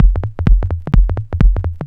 Electrohouse Loop 128 BPM (4).wav